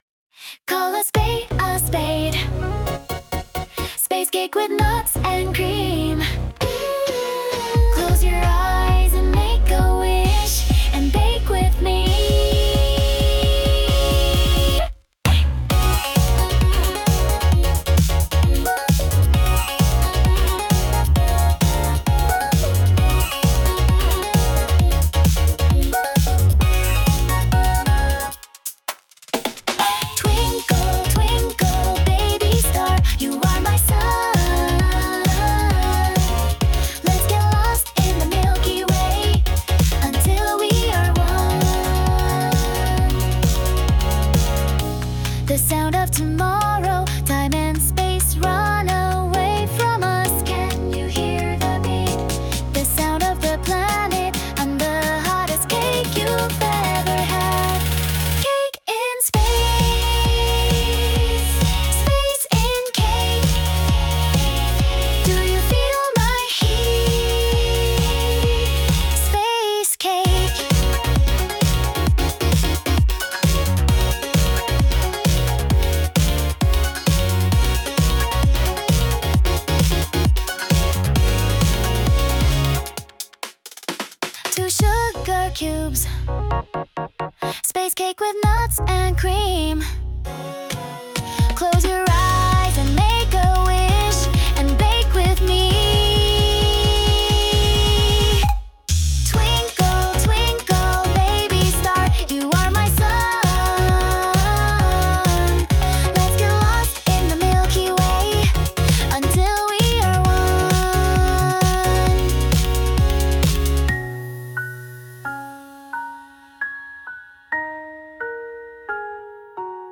• ジャンル：エラーポップアイドル
• 声：透明感のあるヒロインボイス